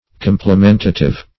Complimentative \Com`pli*men"ta*tive\